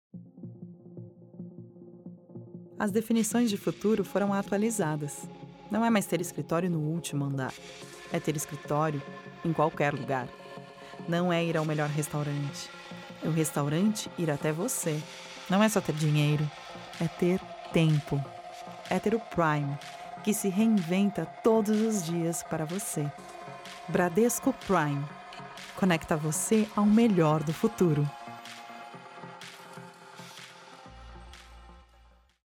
Feminino
Comercial contemporâneo
Voz Jovem 00:32